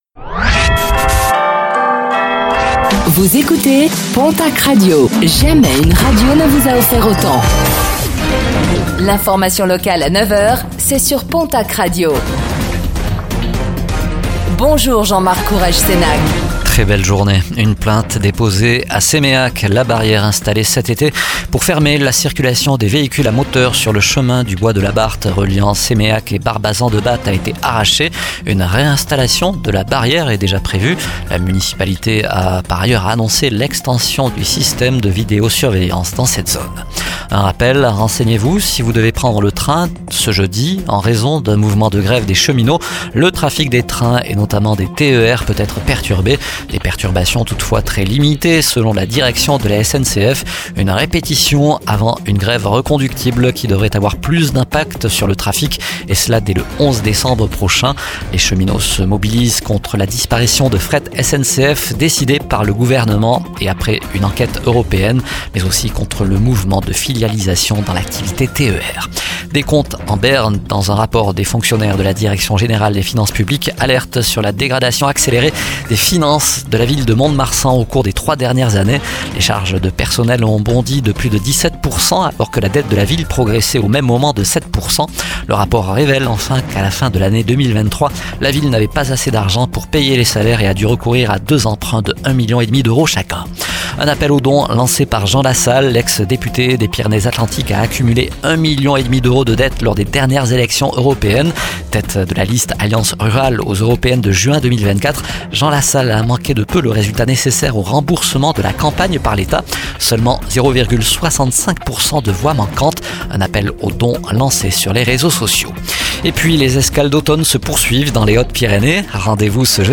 09:05 Écouter le podcast Télécharger le podcast Réécoutez le flash d'information locale de ce jeudi 21 novembre 2024